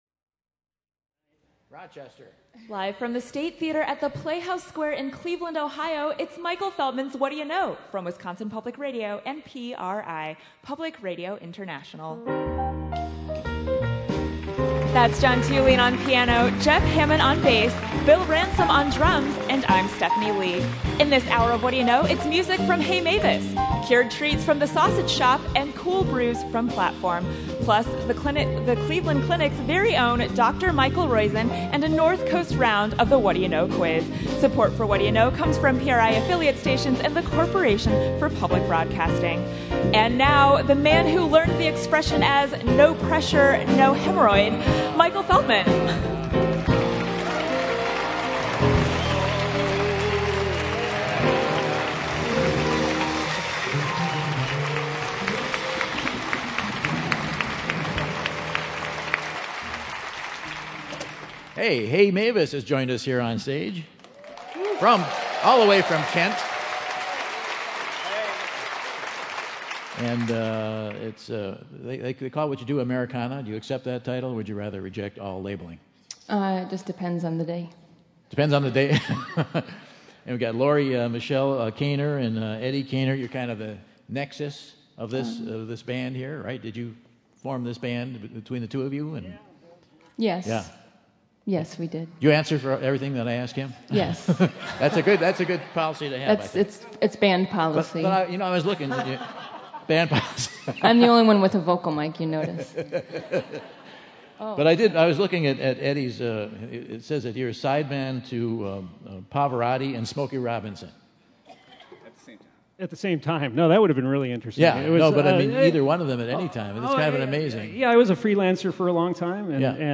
Americana-Roots band